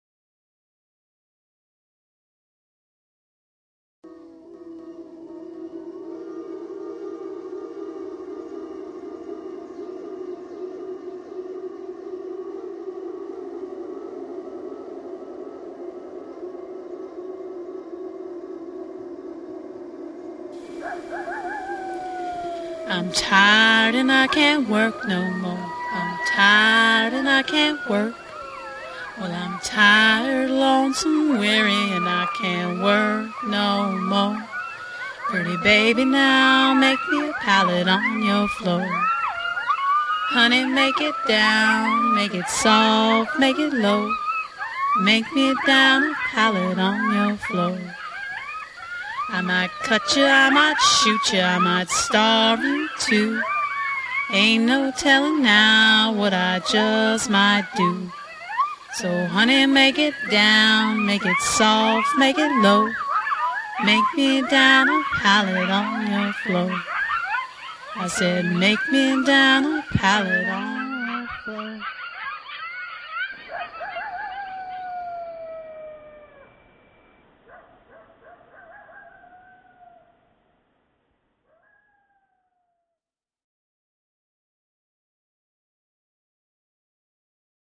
I was thinkin’ about old-time fiddle tunes, an old blues standard, my old friend, the coyote, and an old box that I filled with desert rocks. This also held the sound and was laid down gently on the floor.